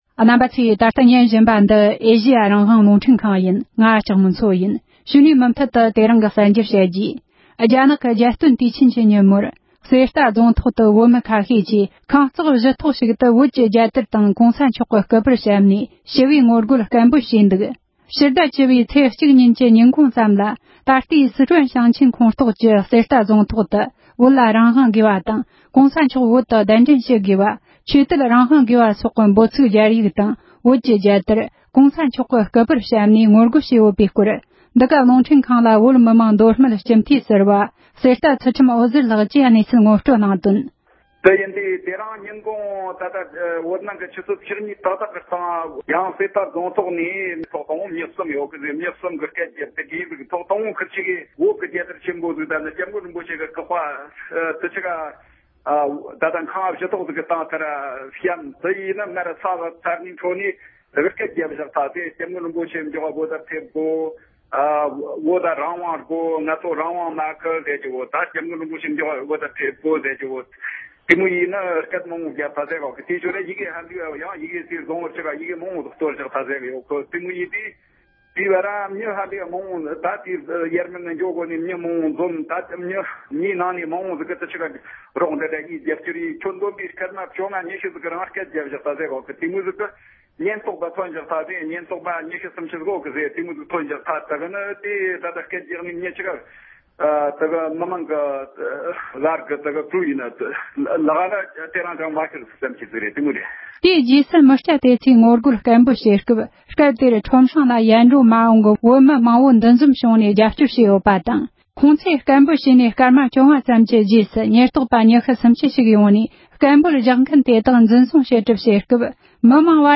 སྒྲ་ལྡན་གསར་འགྱུར། སྒྲ་ཕབ་ལེན།
༄༅༎རྒྱ་ནག་གི་རྒྱལ་སྟོན་དུས་ཆེན་ཉིན་མོར་གསེར་རྟ་རྫོང་ཐོག་ཏུ་བོད་མི་ཁ་ཤས་ཀྱིས་ཁང་རྩེག་བཞི་ཐོག་ཞིག་ཏུ་བོད་ཀྱི་རྒྱལ་དར་དང་། ༸གོང་ས་མཆོག་གི་སྐུ་པར་བཤམས་ནས་ཞི་བའི་ངོ་རྒོལ་དང་སྐད་འབོད་བྱས་ཡོད་པའི་སྐོར། བོད་མི་མང་མདོ་སྨད་སྤྱི་འཐུས་ཟུར་བ་གསེར་རྟ་ཚུལ་ཁྲིམས་འོད་ཟེར་ལགས་ཀྱིས། འདི་ག་ཨེ་ཤེ་ཡ་རང་དབང་རླུང་འཕྲིན་ཁང་ལ་གནས་ཚུལ་ངོ་སྤྲོད་གནང་བར་གསན་རོགས་ཞུ༎